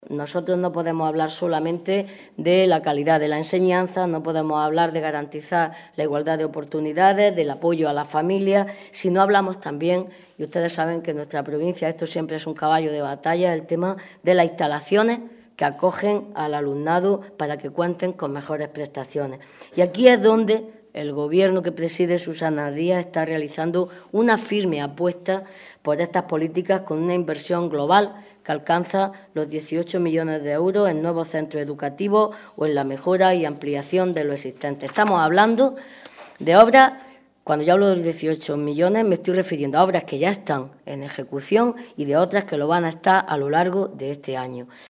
Rueda de prensa sobre educación que ha ofrecido la parlamentaria socialista Adela SeguraRueda de prensa sobre educación que ha ofrecido la parlamentaria socialista Adela Segura